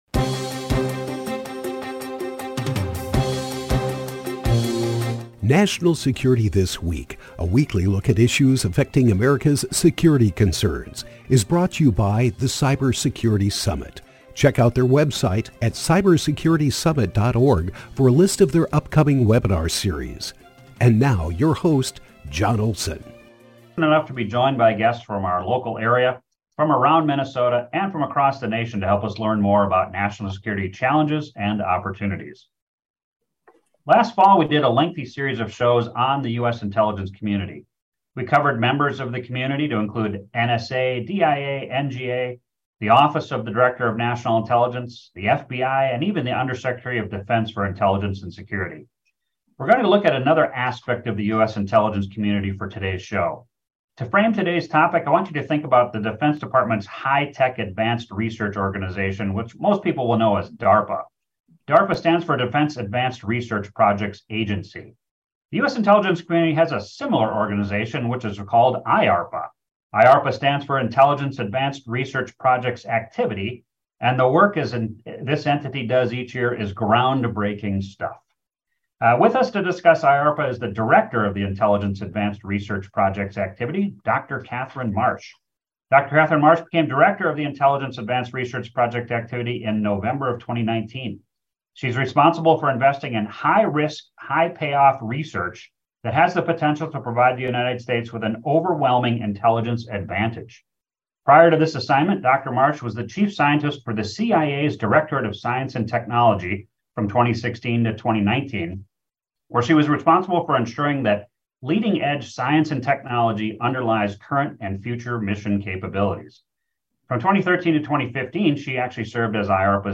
discusses new intelligence collection, processing, and analytical capabilities, with Dr. Catherine marsh, the Director of the US Intelligence Community’s cutting edge organization the Intelligence Advanced Research Projects Activity.